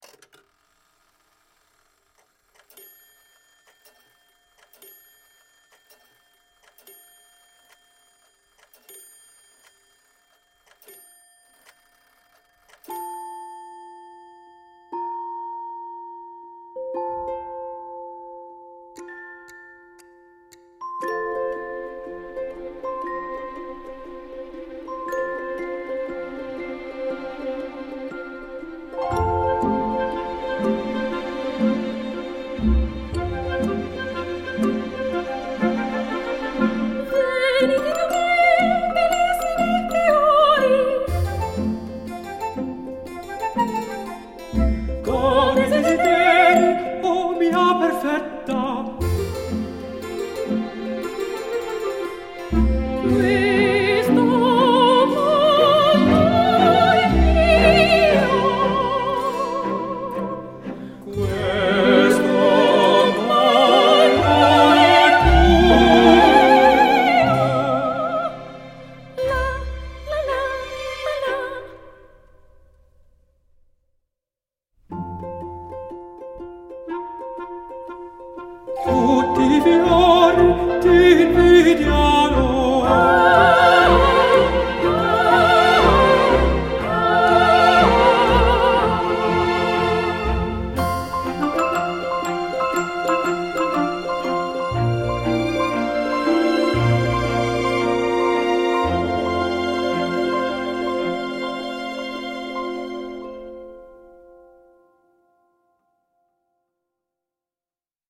ambiance chambriste XIXème
Ce score est virevoltant et enjoué